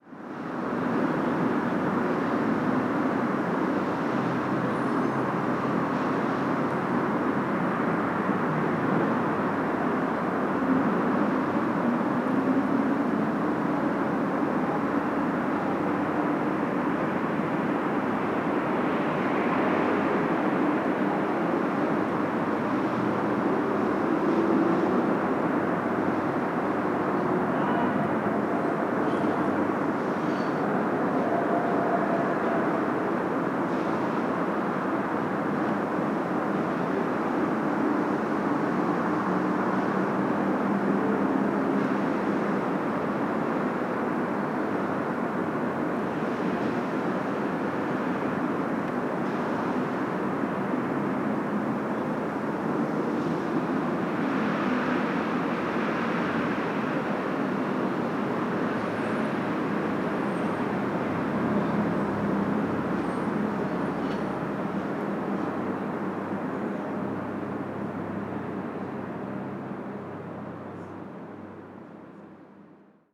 Fondo de tráfico de la ciudad de Nueva York, Estados Unidos